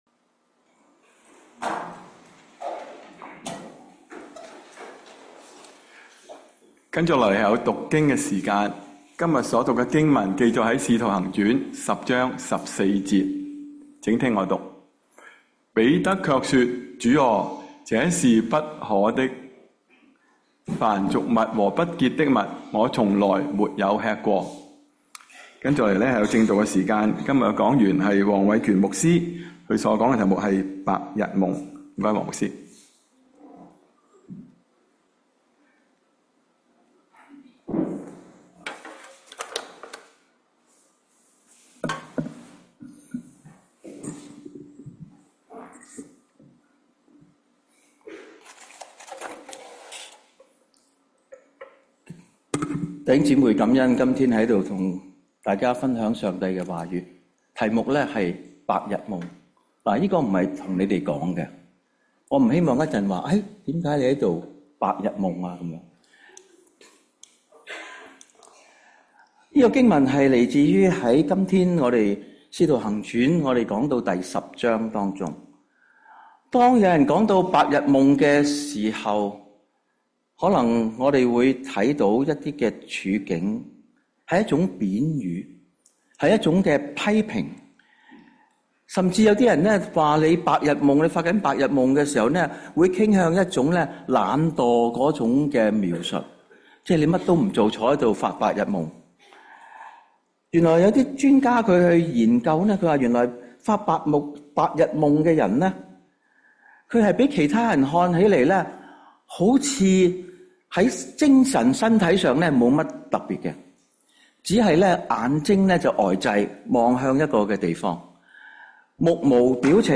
粵語堂主日崇拜-《白日夢》-《使徒行傳10-14節》.mp3